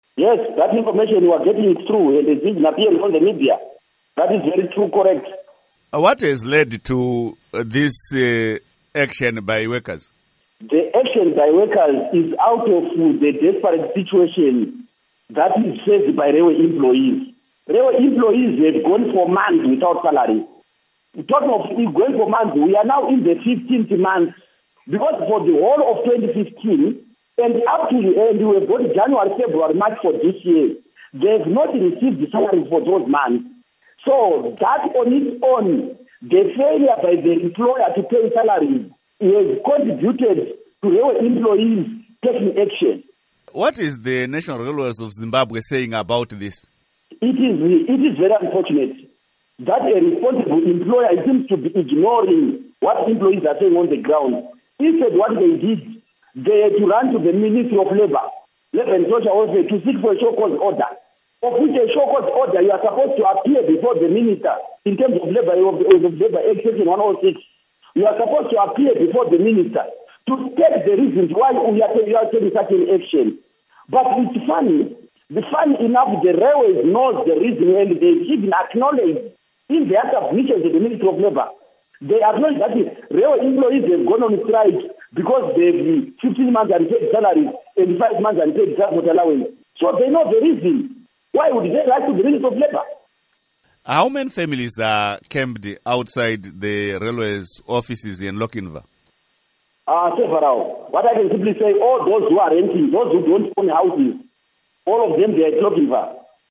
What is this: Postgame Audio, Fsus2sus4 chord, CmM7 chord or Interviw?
Interviw